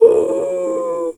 seal_walrus_2_death_02.wav